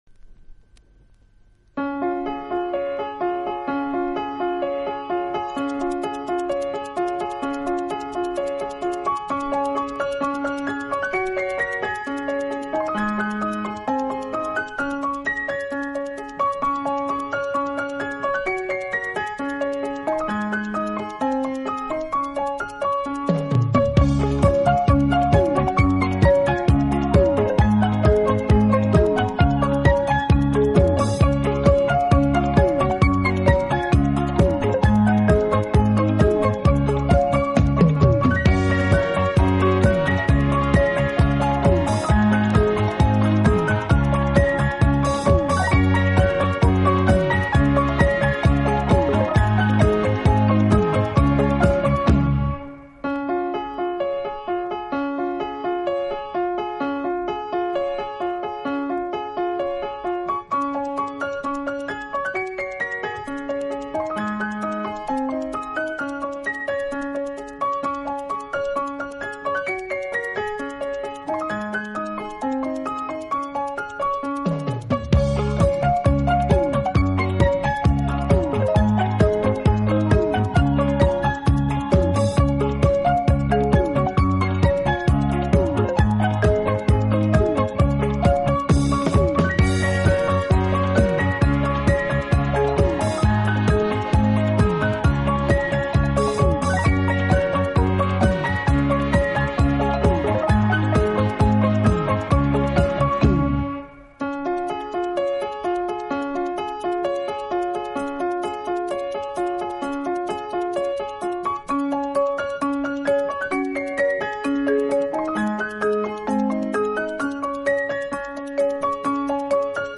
【轻音乐专辑】
演奏轻柔优美，特別是打击乐器的演奏，具有拉美音乐独特的韵味。